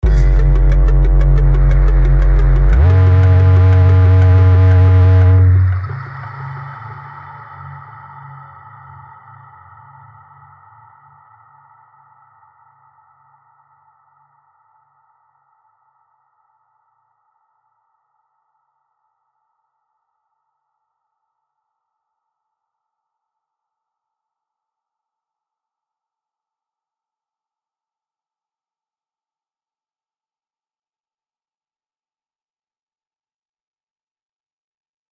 the library soundtrack